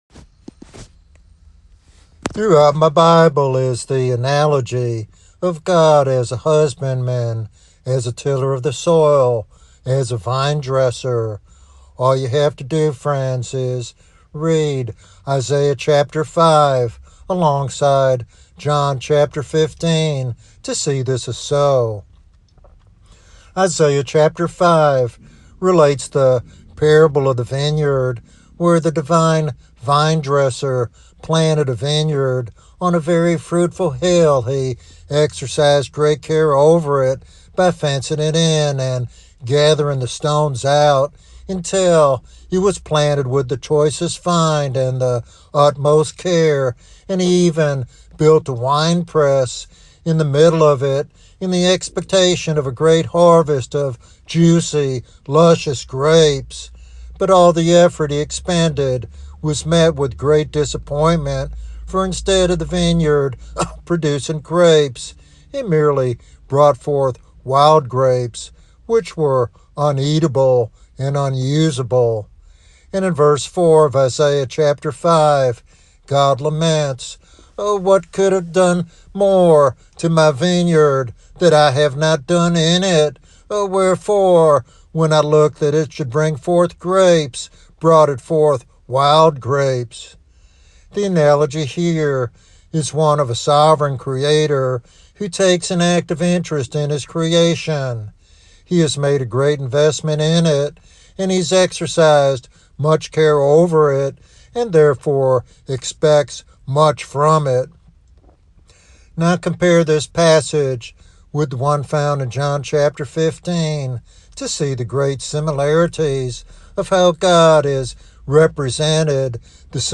He calls for a willingness to submit to God's refining work, encouraging a life of consecration over comfort. This sermon inspires listeners to understand their role as fruit bearers and to trust God's sovereign hand in their spiritual growth.